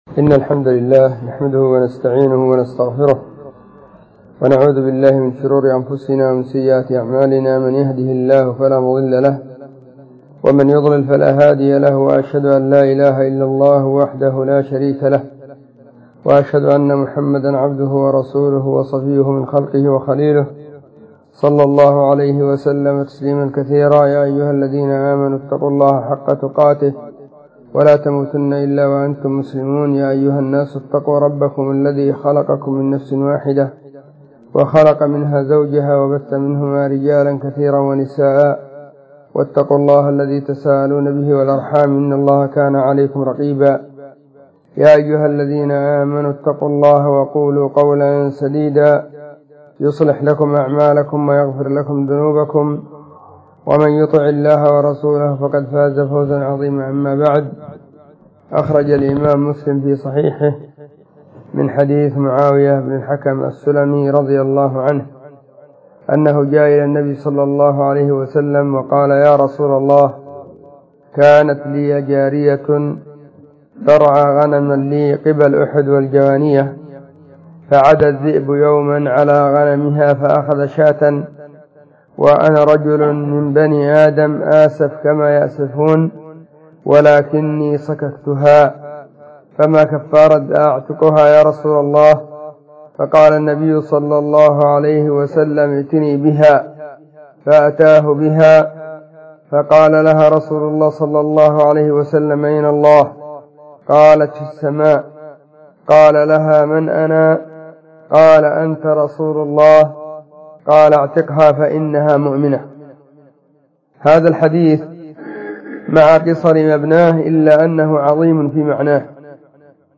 🎙 كلمة قيمة بعنوان: أين الله في مصلى الخليل المصطفى بالقاهرة فجر 19 من ربيع الأول 1443
📢 مسجد – الصحابة – بالغيضة – المهرة، اليمن حرسها الله.